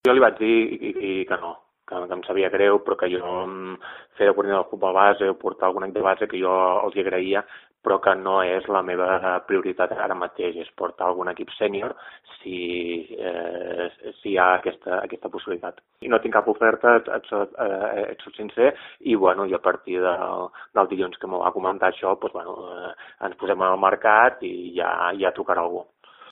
en declaracions als micròfons del programa Minut a Minut